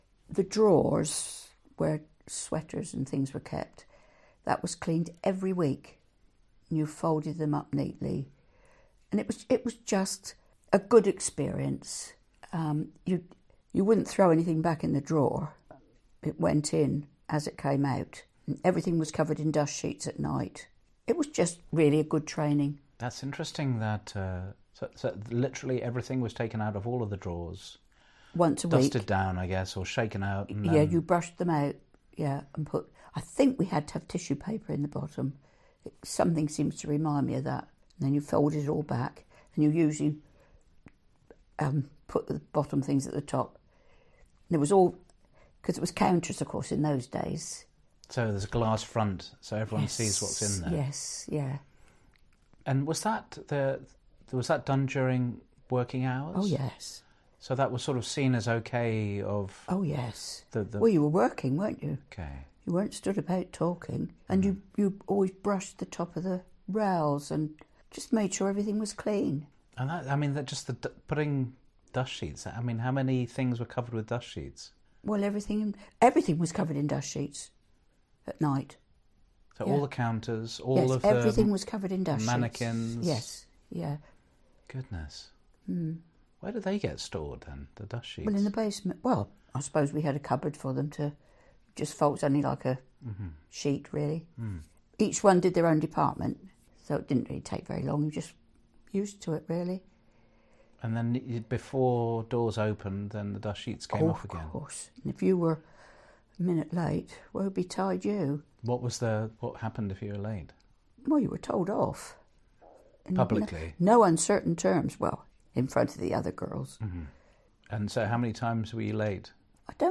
Packs' People Oral History project